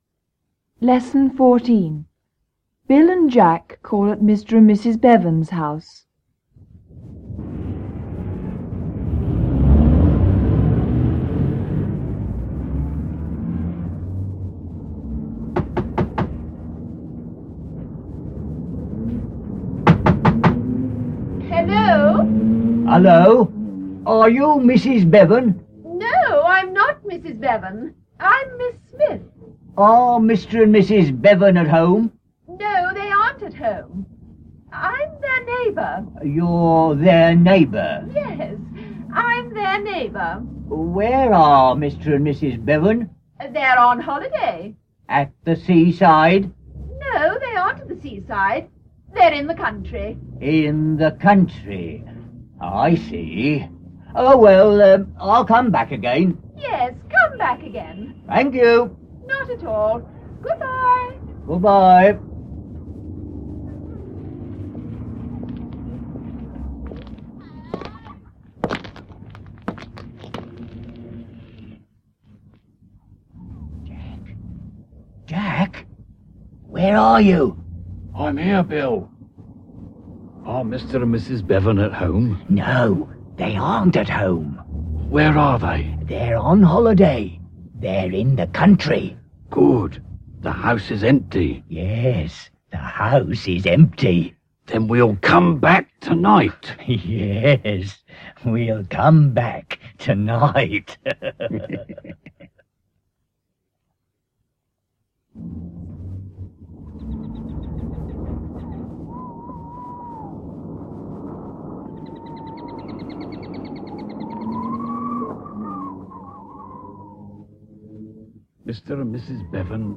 Lesson fourteen